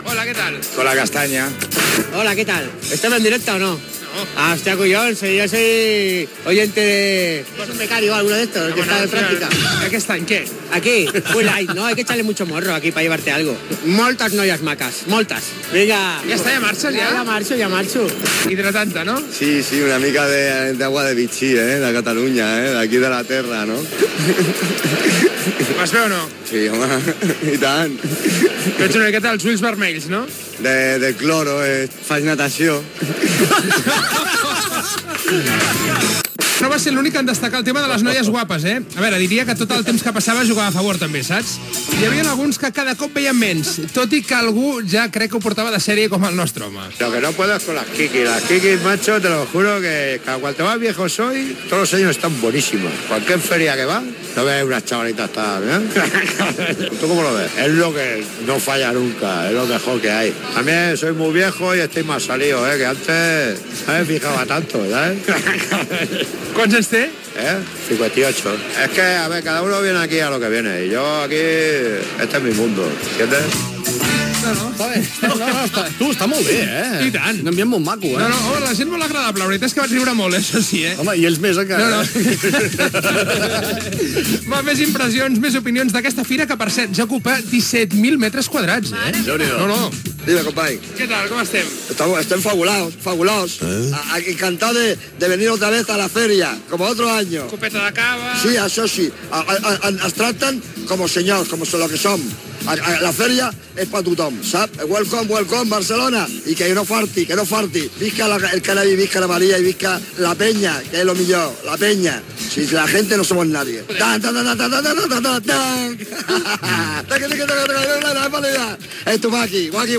Reportatge a la fira Spannabis